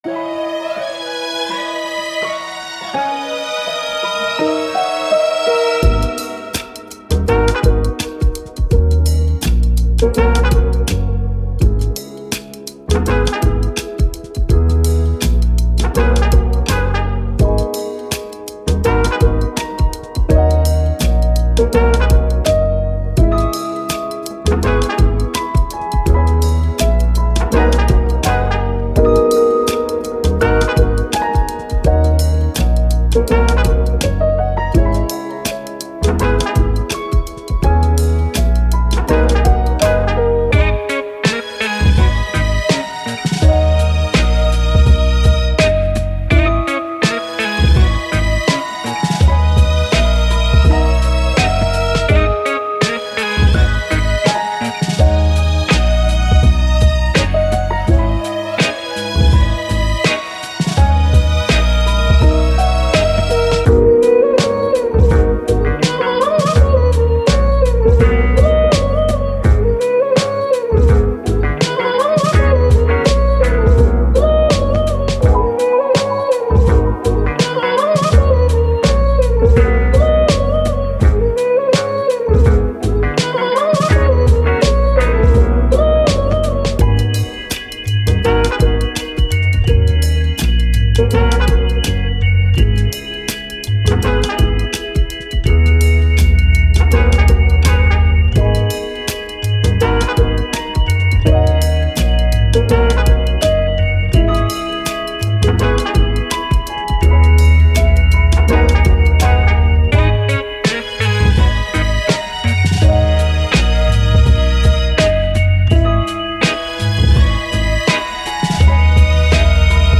Soul, Hip Hop, Vintage, Brass, Thoughtful